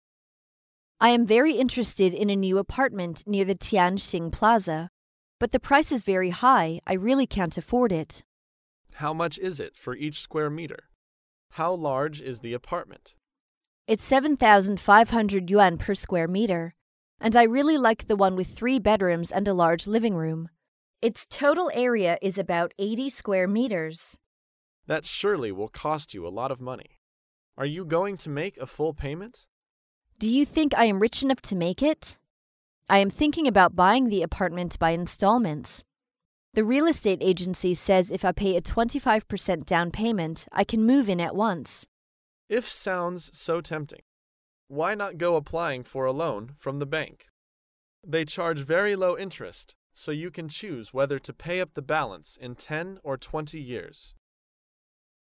在线英语听力室高频英语口语对话 第431期:申请住房贷款的听力文件下载,《高频英语口语对话》栏目包含了日常生活中经常使用的英语情景对话，是学习英语口语，能够帮助英语爱好者在听英语对话的过程中，积累英语口语习语知识，提高英语听说水平，并通过栏目中的中英文字幕和音频MP3文件，提高英语语感。